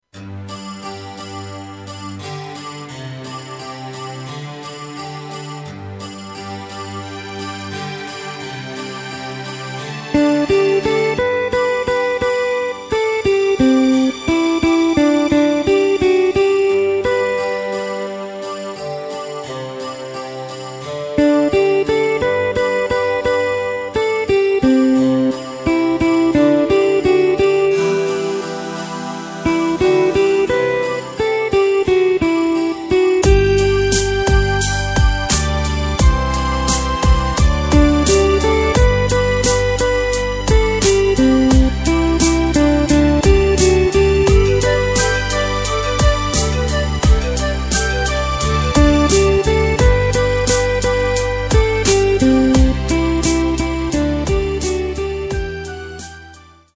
Takt:          4/4
Tempo:         87.00
Tonart:            G
Schlager Neuaufnahme aus dem Jahre 2012!